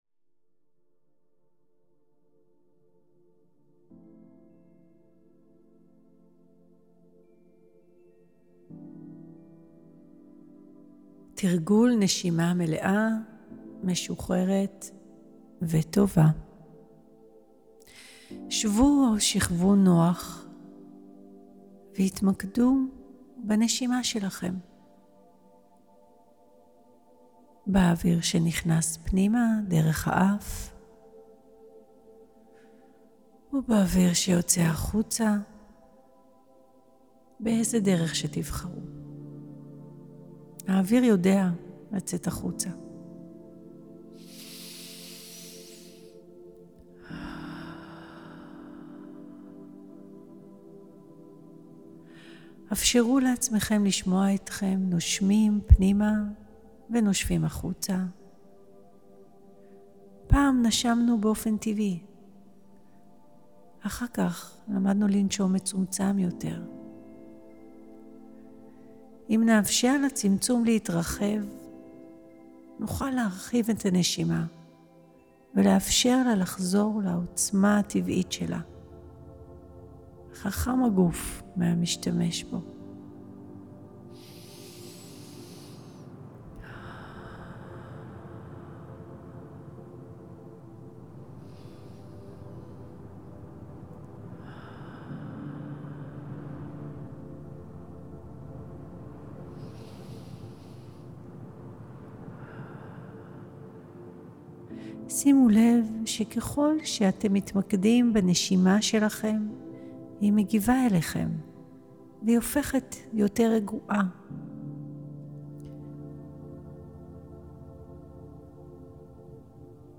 מדיטציית נשימה
הקלטתי בה שילוב של דמיון מודרך, הרפיה ופיתוח מודעות לקשר בין גוף ונפש דרך הכרות עם סגנון הנשימה האישי ואיך אפשר לשפר אותו.
במדיטציה הנוכחית, יש הרפיה , ואחריה תרגול מדיטטיבי של נשימה מודעת ומלאה.